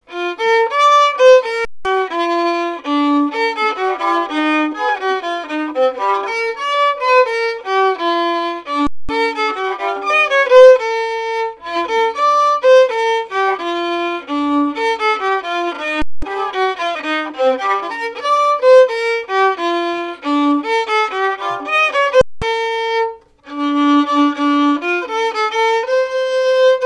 Marche nuptiale n° 3
marches de noces jouées aux Gueurnivelles
Pièce musicale inédite